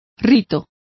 Complete with pronunciation of the translation of rites.